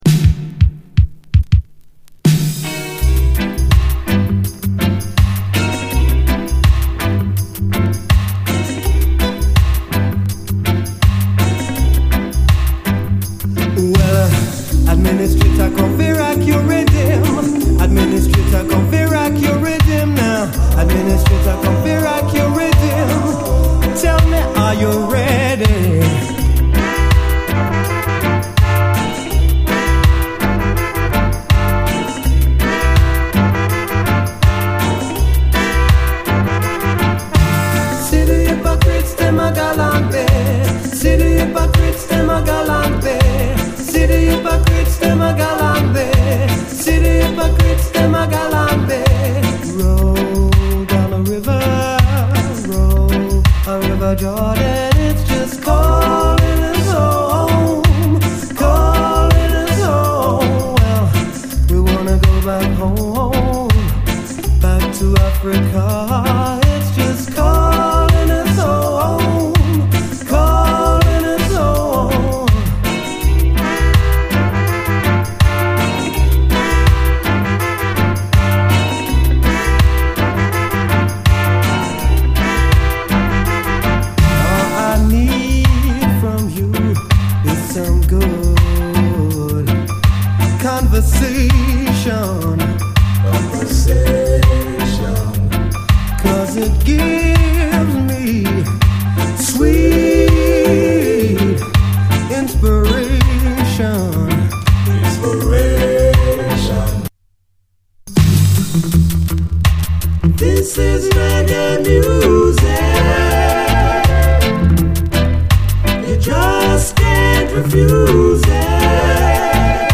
レゲエ名曲をメドレーでカヴァーした、爽やかでライトなメロウUKラヴァーズ！
爽やかでライトなリゾート感が心地よいメロウ・ラヴァーズ！あまりメドレーという感じもしないです。